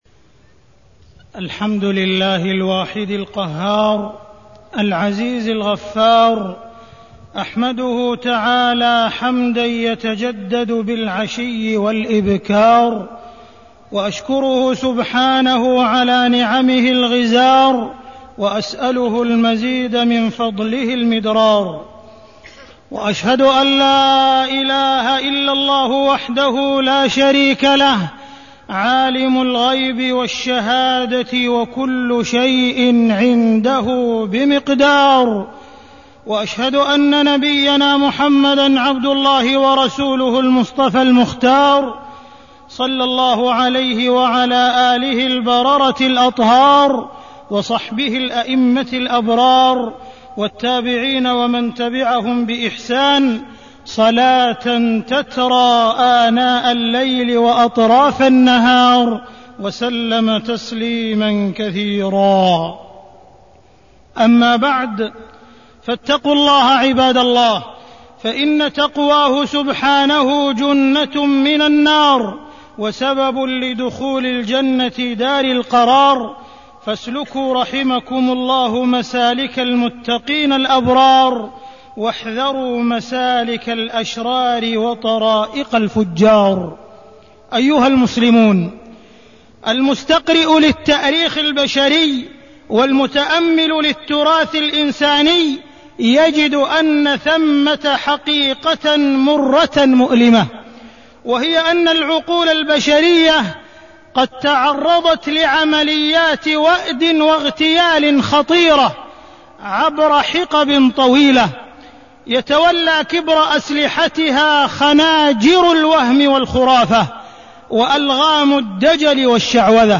تاريخ النشر ١٠ صفر ١٤٢٢ هـ المكان: المسجد الحرام الشيخ: معالي الشيخ أ.د. عبدالرحمن بن عبدالعزيز السديس معالي الشيخ أ.د. عبدالرحمن بن عبدالعزيز السديس الشعوذة والخرافة والسحر